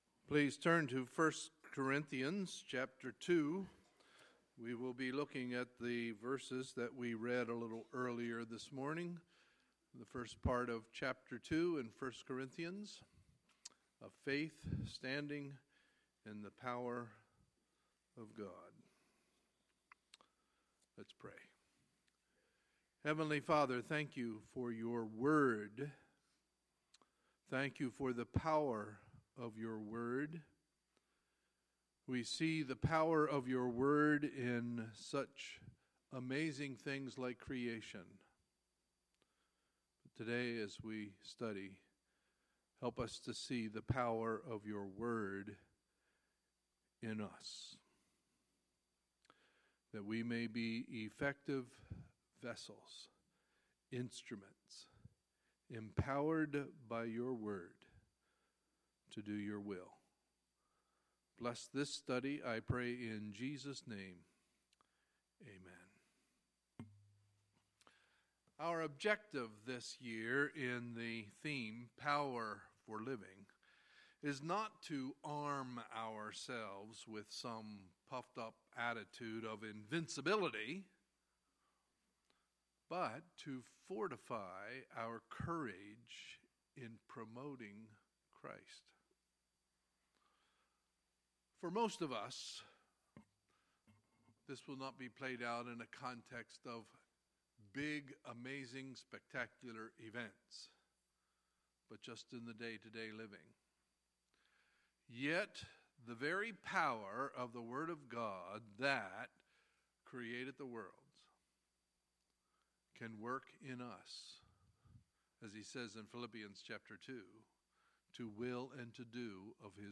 Sunday, February 5, 2017 – Sunday Morning Service